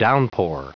Prononciation du mot downpour en anglais (fichier audio)
Prononciation du mot : downpour